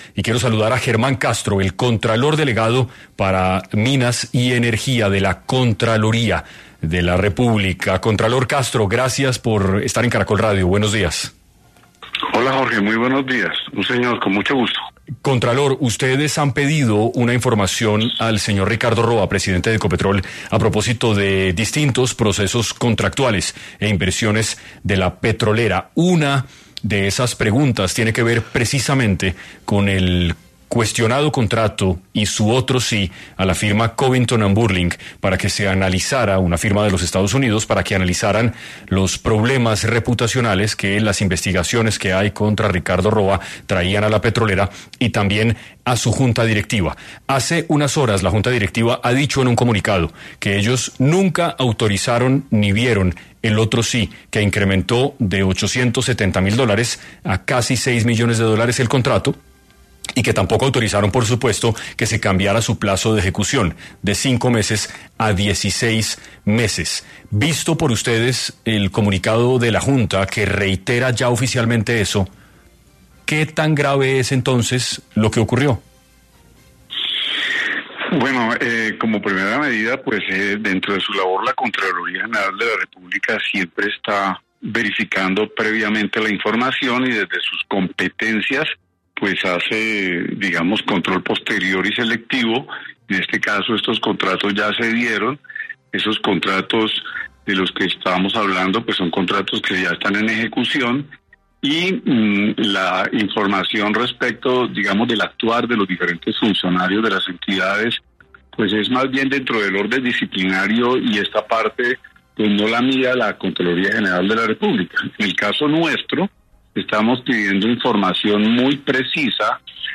El Contralor delegado de Minas y Energía, Germán Castro, estuvo en 6AM de Caracol Radio para conversar sobre Ecopetrol y los escándalos que hay alrededor de esta compañía.